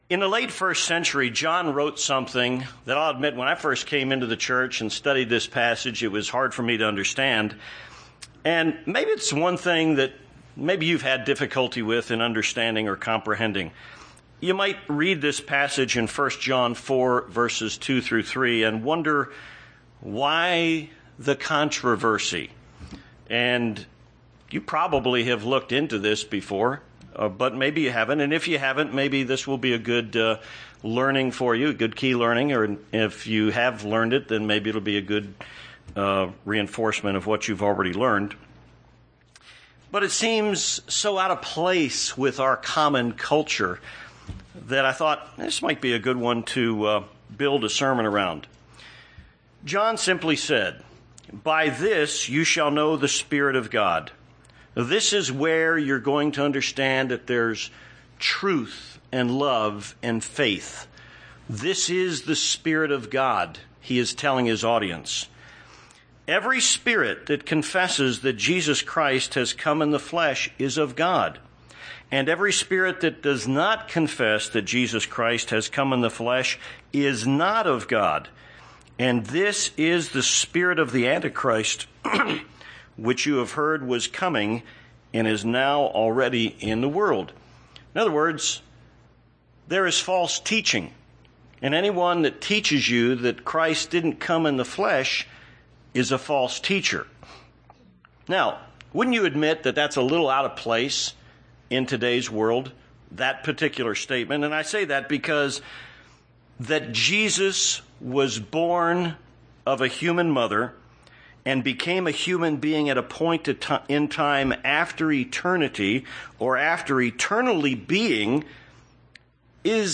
Given in Tucson, AZ
UCG Sermon Studying the bible?